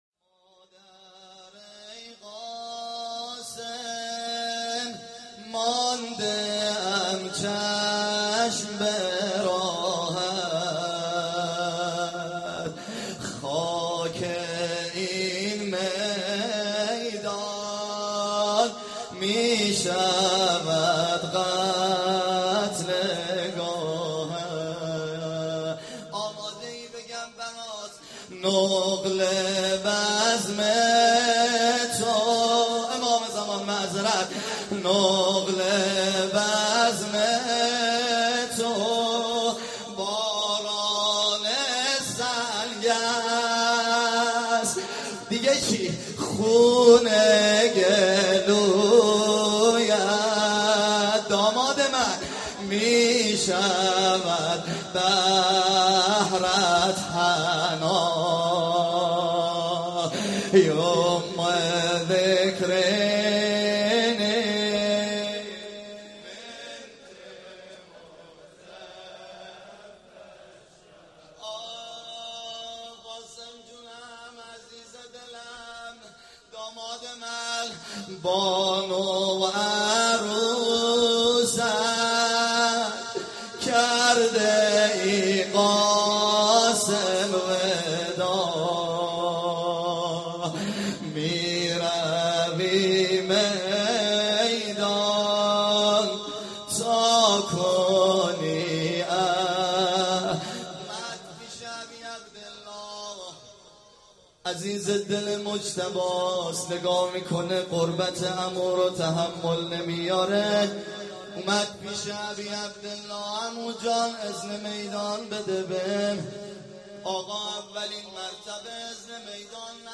محرم 94